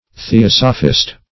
Theosophist \The*os"o*phist\, n.